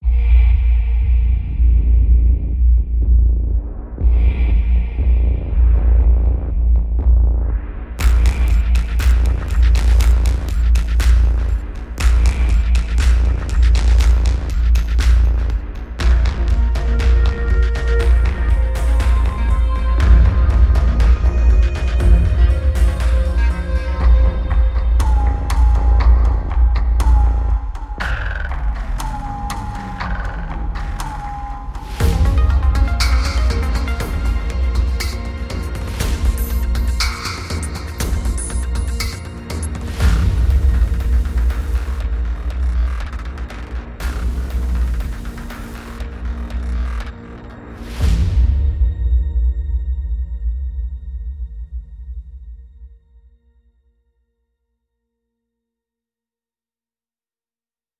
节奏打击乐 Sample Logic Drum Fury Motion KONTAKT-音频fun
Sample Logic Drum Fury Motion 是一款节奏设计工具，它将打击乐器和动态运动融合在一起，创造出富有表现力和灵活的循环序列。
它使用了多采样的声学、数字、人声和声音设计打击乐，通过步进和效果动画器来赋予它们生命。
它是核聚变节奏的演变，以惊人的方式将传统打击乐和现代声音设计相结合。